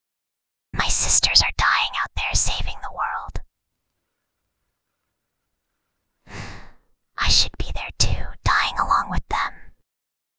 Whispering_Girl_12.mp3